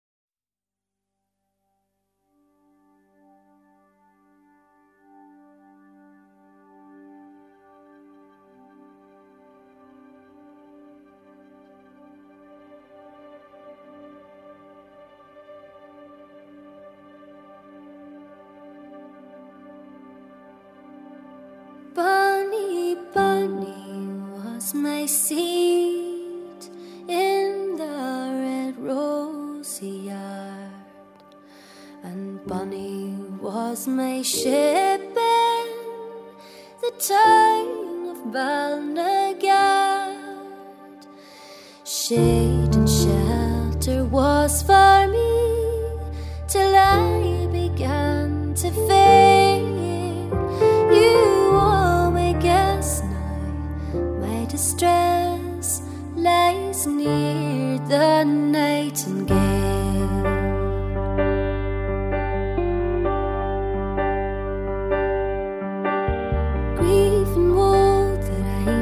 她的歌有一种山谷风般的独傲和冷酷，歌曲象是从深处旋来又回荡而去。
她的声音真的很美，空灵干净带着淡淡的忧伤，然而又充满了温暖的气息和坚定的信仰，独处的时候倾听，再适合不过。。。。